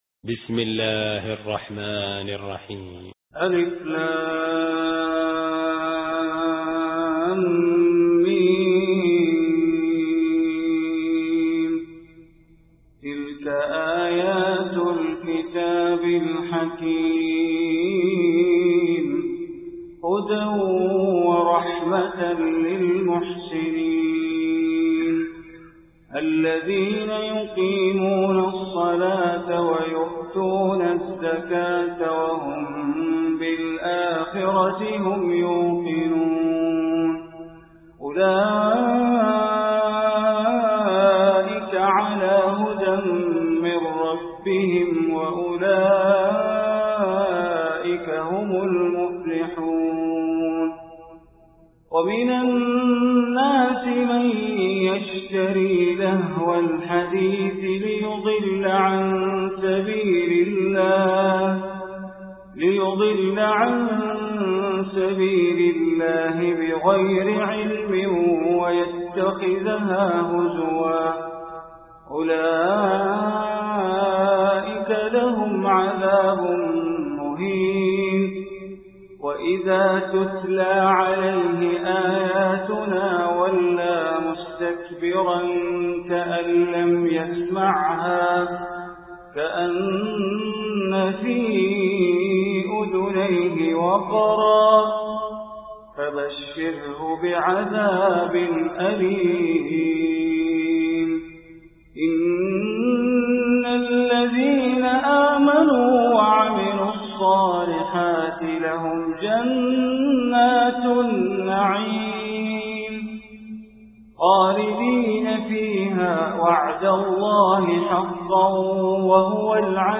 Surah Luqman Recitation by Sheikh Bandar Baleela